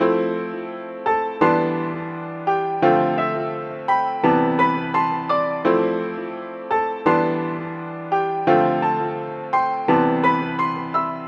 描述：全长的嘻哈节拍，向商业嘻哈摇摆。
Tag: 贝斯鼓 电子 嘻哈 器乐 循环 流行